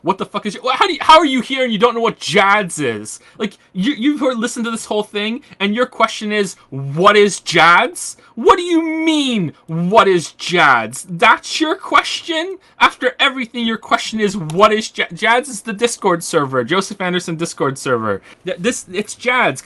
88 KB The audio of the original What is JADS? speech. 1